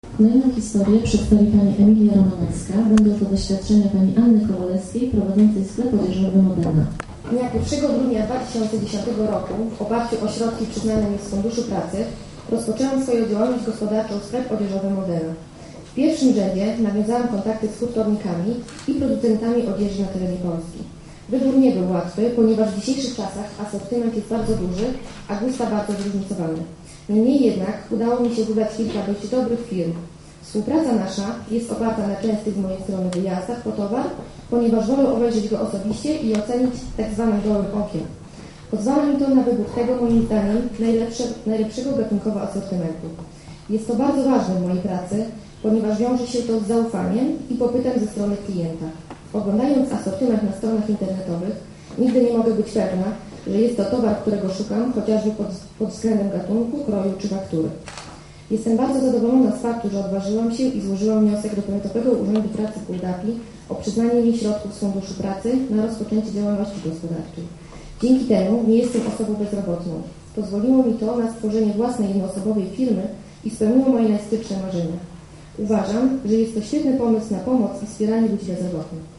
Bardzo ciekawym punktem było odczytanie wypowiedzi przedsiębiorców, którzy uzyskali pomoc z PUP na rozpoczęcie działalności, o ich pomyśle na biznes i jego realizacji.